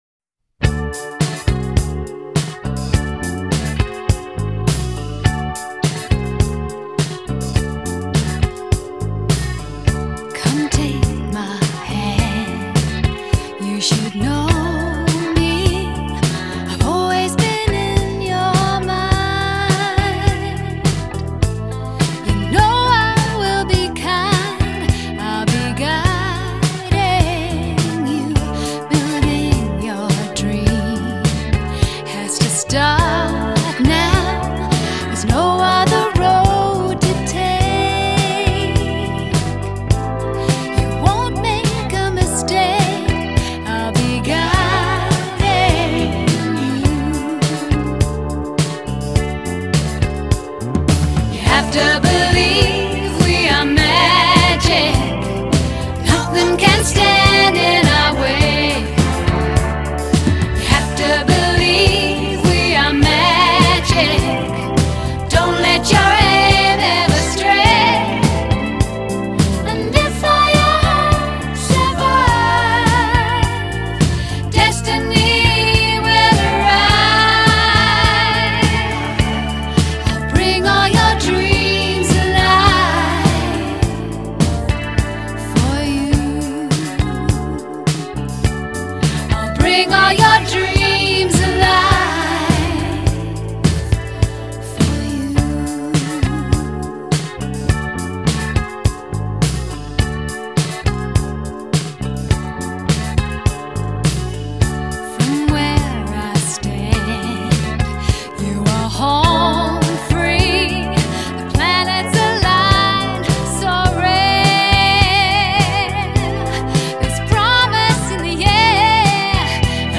Soundtrack from the Motion Picture